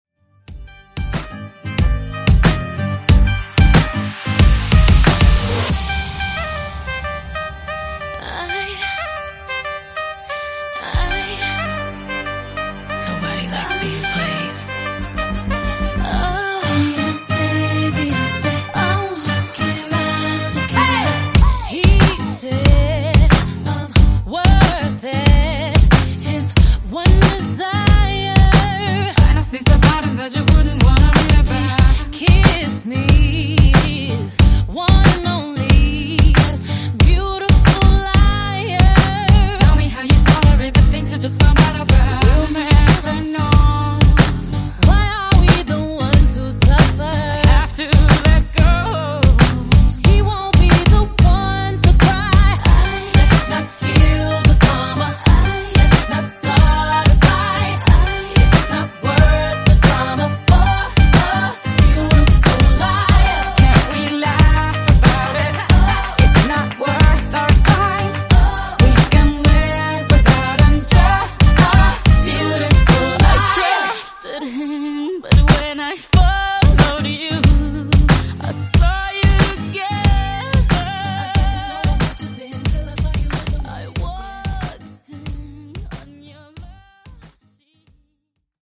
Genres: RE-DRUM , REGGAETON
Clean BPM: 100 Time